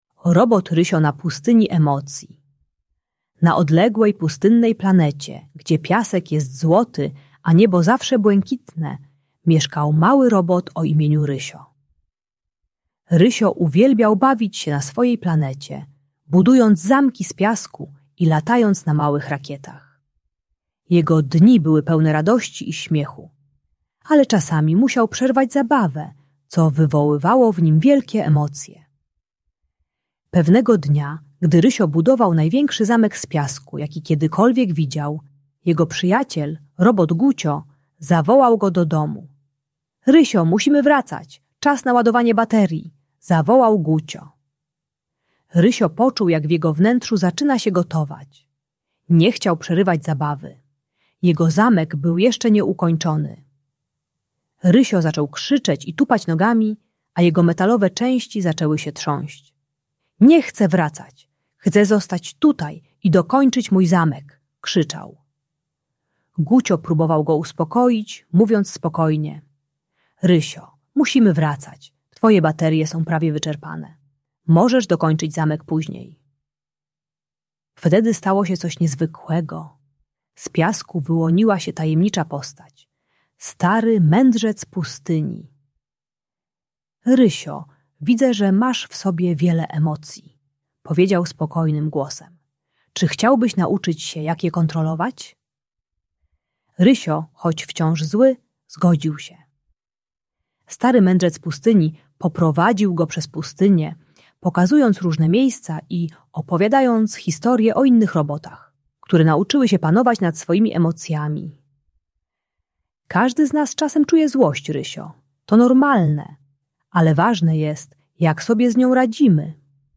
Robot Rysio na pustyni emocji - Bunt i wybuchy złości | Audiobajka